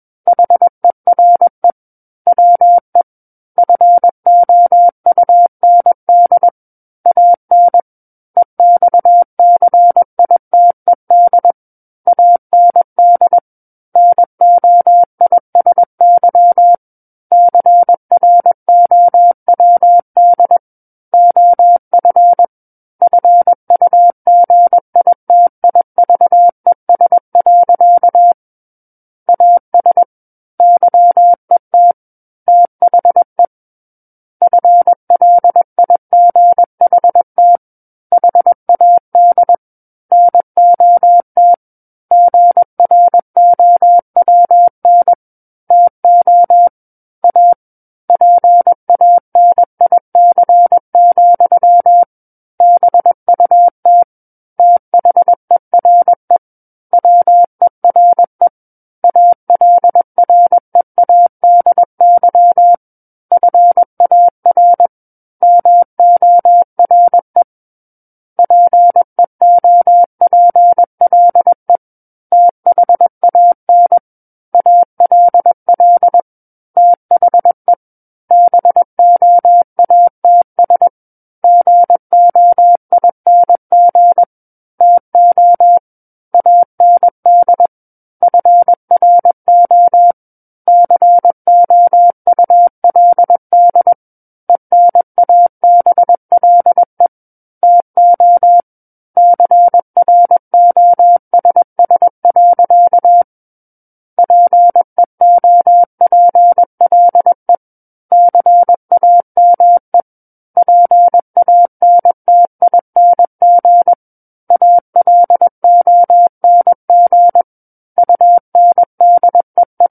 War of the Worlds - 12-Chapter 12 - 21 WPM